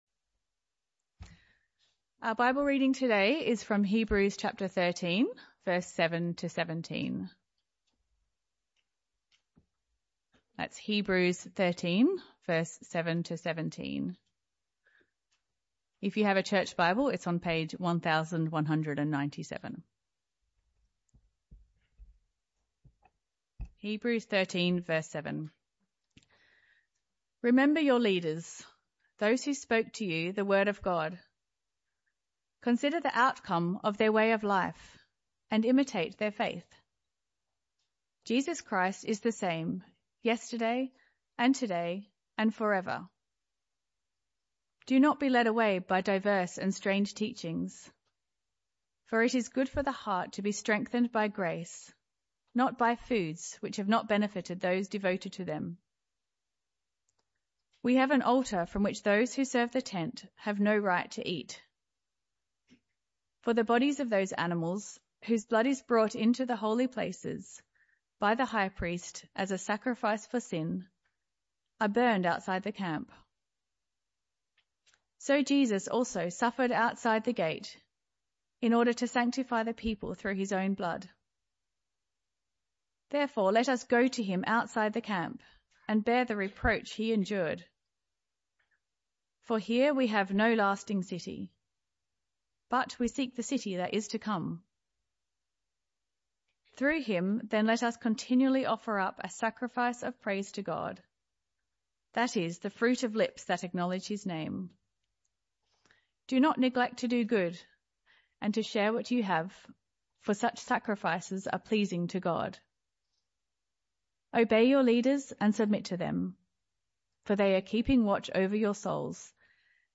This talk was part of the AM Service series entitled True Worship.